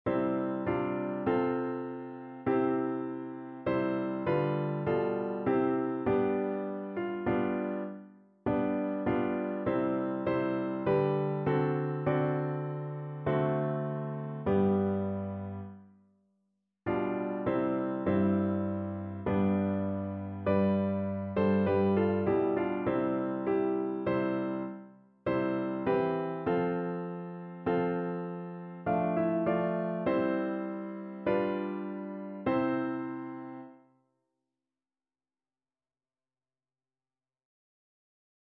Notensatz 1 (4 Stimmen gemischt)